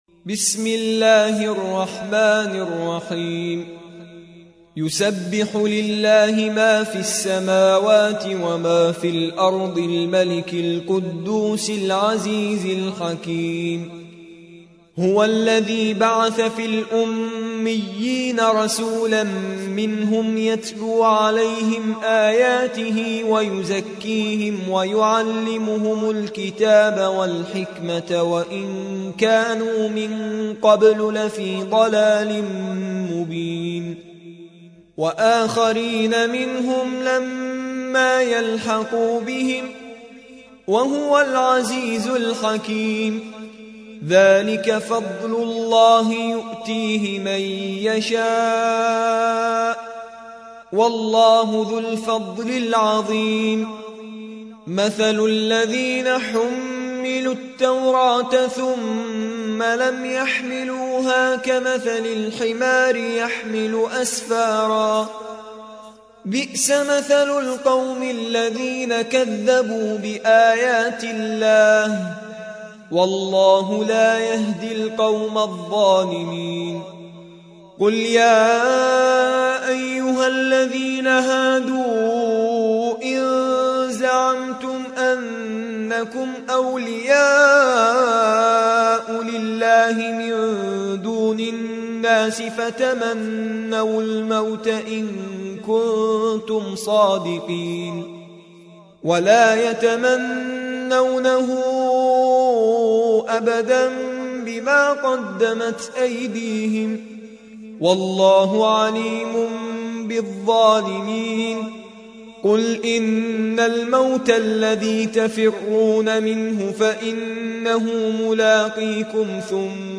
62. سورة الجمعة / القارئ